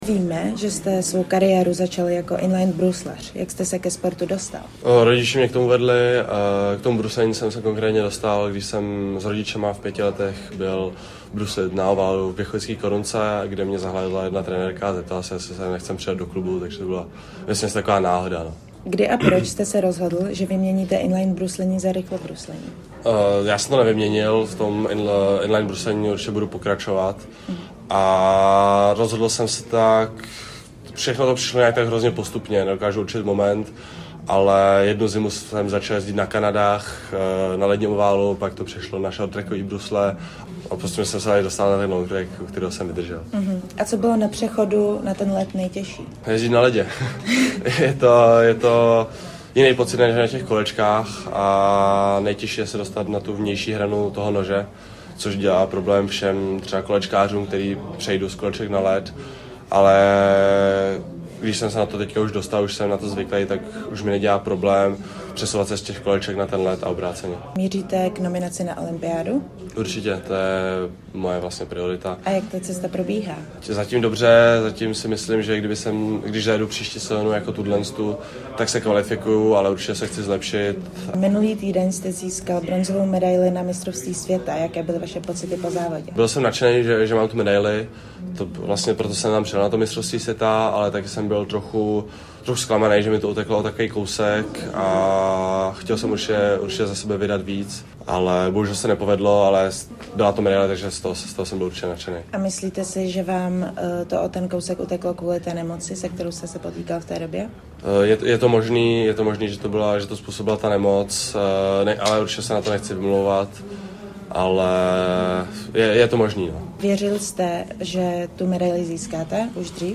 Nejen o úspěchu na šampionátu jsme s ním mluvili ve vysílání Rádia Prostor.
Rozhovor s rychlobruslařem Metodějem Jílkem